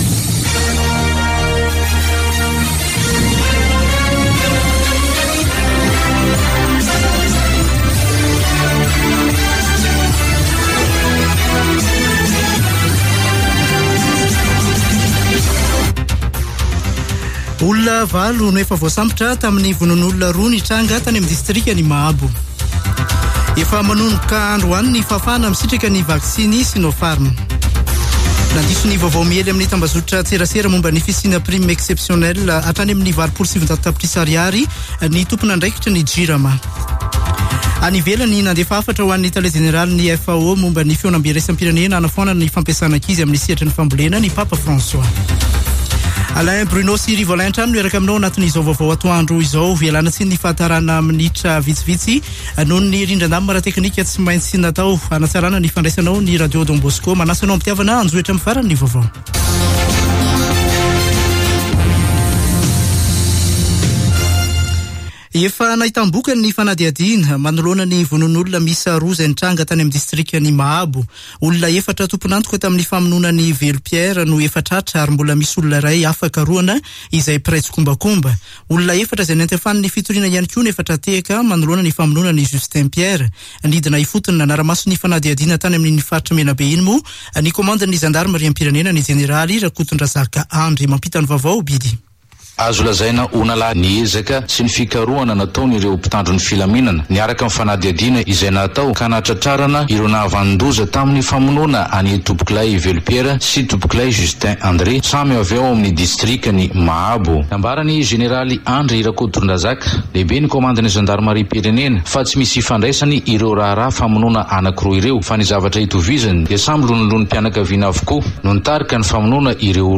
[Vaovao antoandro] Alakamisy 04 novambra 2021